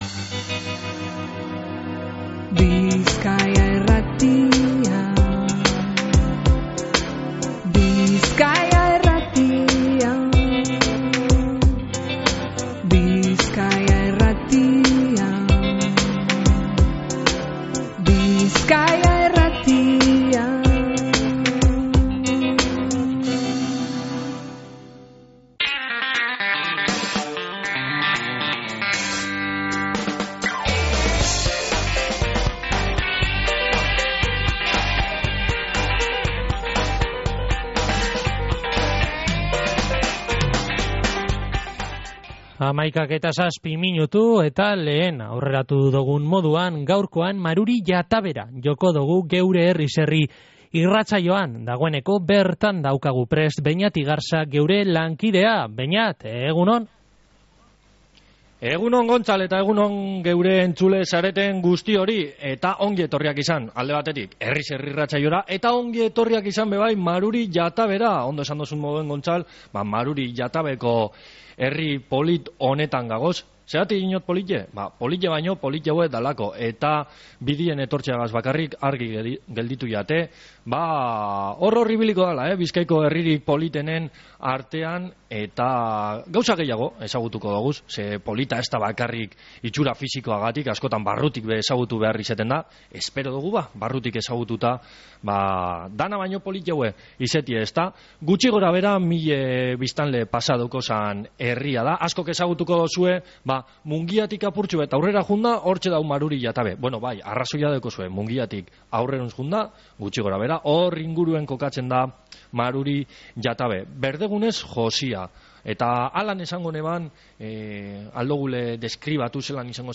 Maruri-Jatabera etorri gara "neguko" jaialdiak bertotik bizitzera